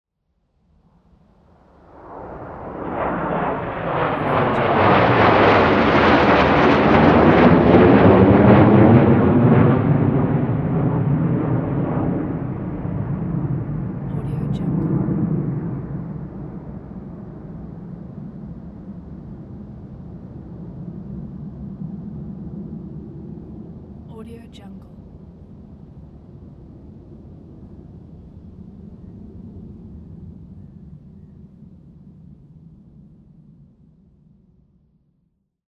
دانلود افکت صوتی شهری
دانلود افکت صوتی صدای سه هواپیمای جت نظامی در حال پرواز 4